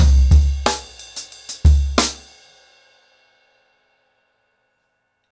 InDaHouse-90BPM.41.wav